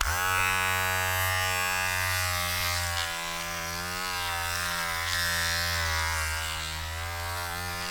SHAVER 1  -S.WAV